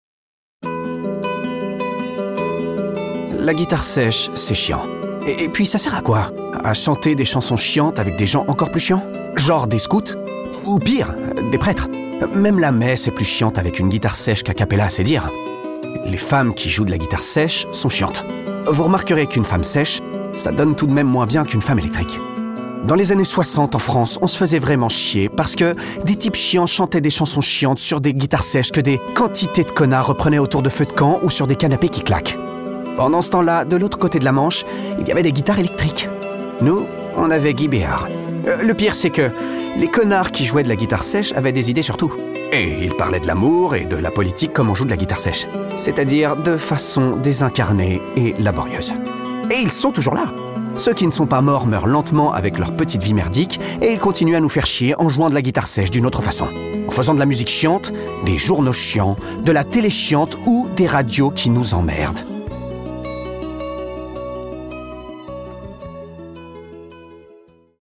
Pub Guitare Sèche